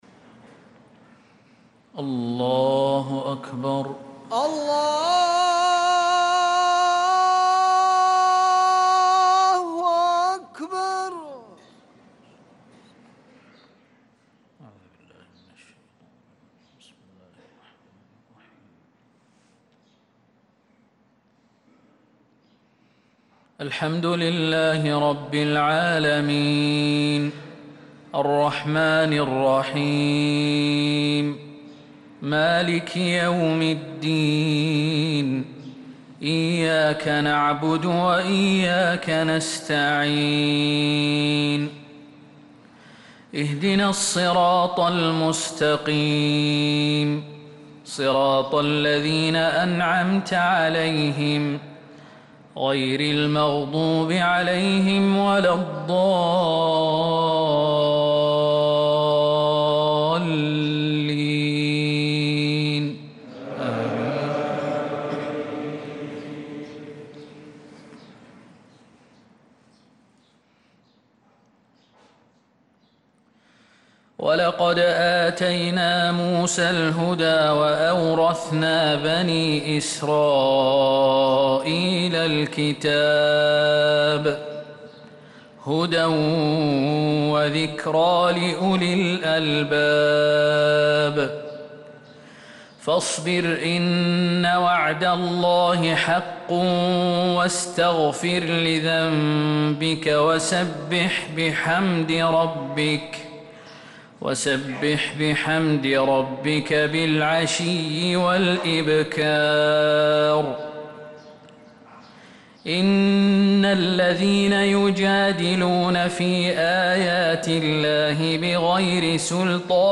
صلاة الفجر للقارئ خالد المهنا 22 ربيع الأول 1446 هـ
تِلَاوَات الْحَرَمَيْن .